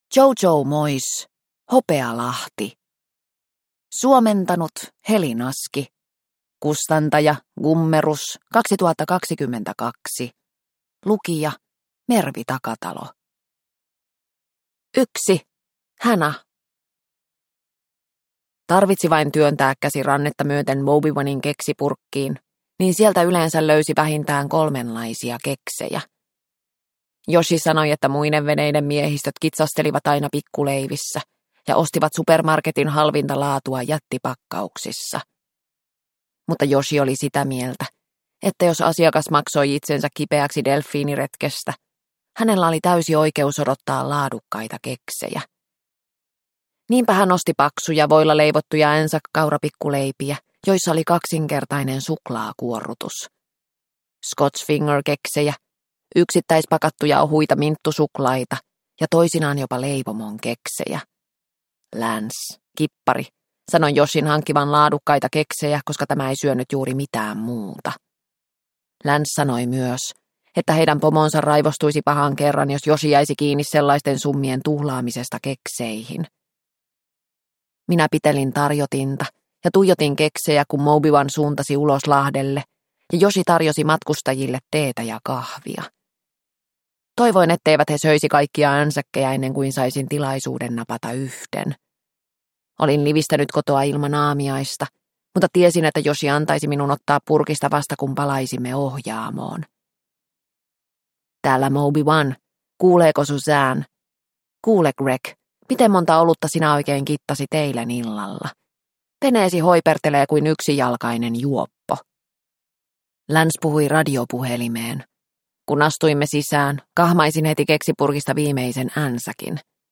Hopealahti – Ljudbok – Laddas ner